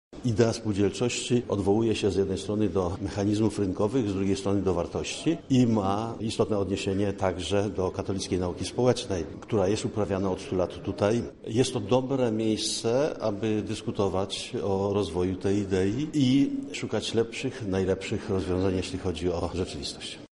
Tak uważają organizatorzy Forum Spółdzielców którzy spotkali się dzisiaj na Katolickim Uniwersytecie Lubelskim.
-Spółdzielnie to jeden z filarów życia społecznego – mówi ksiądz profesor Antoni Dębiński, rektor KUL.